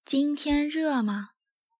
ジン ティェン ルァ マー